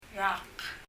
パラオ語 PALAUAN language study notes « water 水 mountain 山 » year 年 rak [rʌk] 英） year 日） 年 Leave a Reply 返信をキャンセルする。